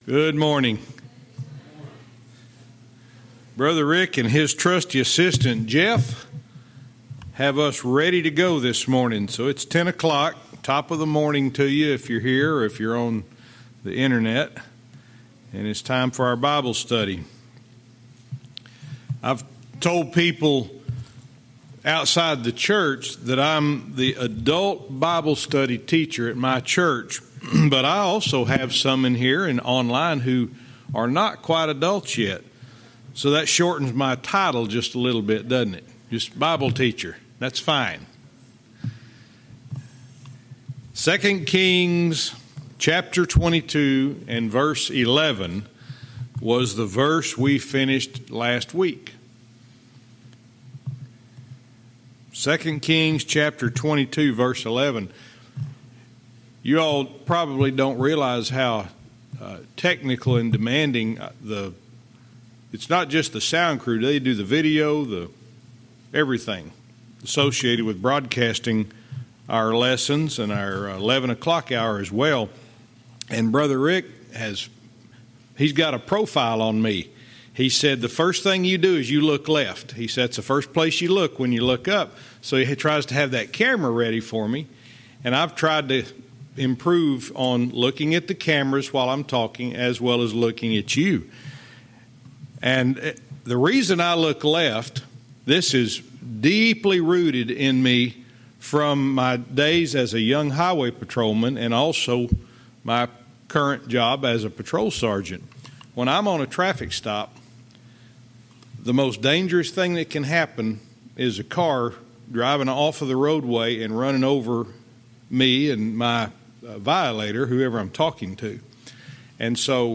Verse by verse teaching - 2 Kings 22:12-13